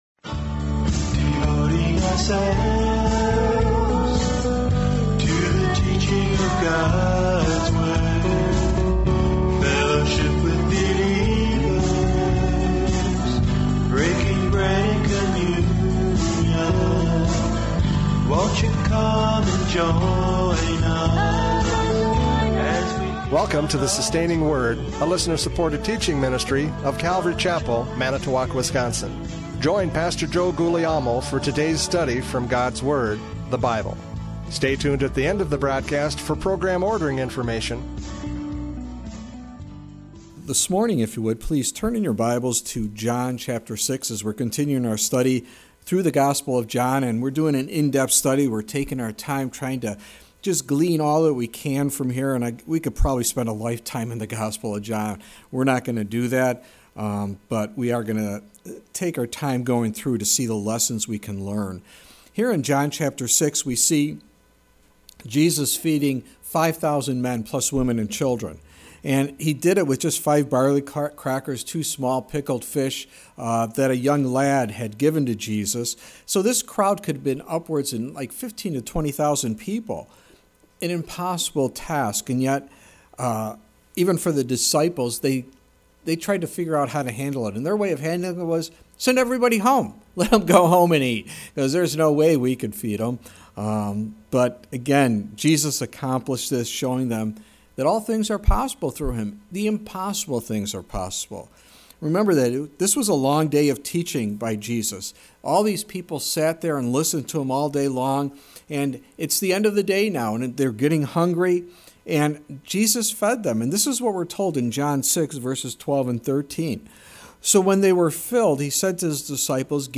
John 6:22-29 Service Type: Radio Programs « John 6:15-21 The Eye of the Storm!